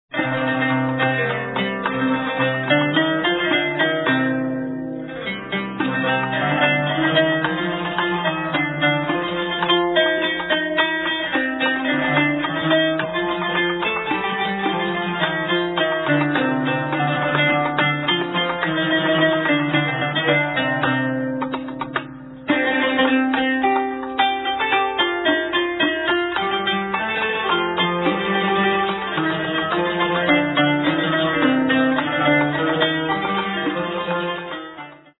Enchanting classical Arabic and Turkish music
qanun